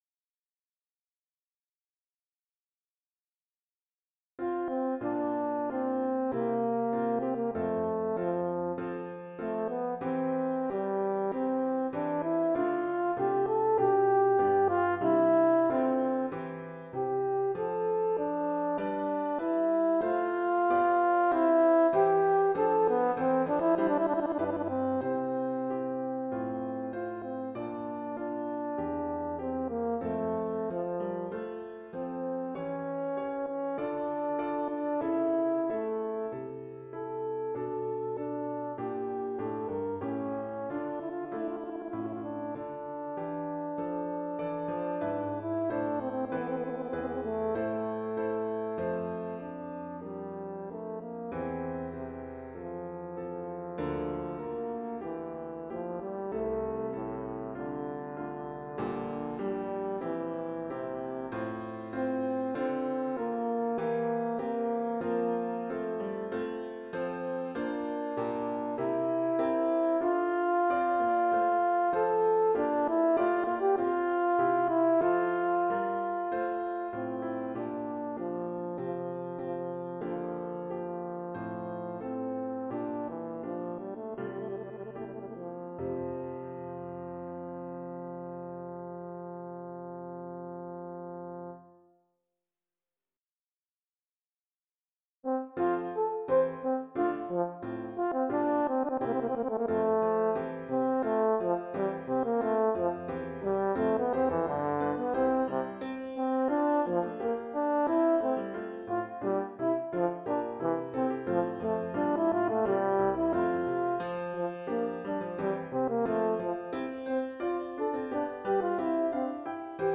Voicing: FH w Piano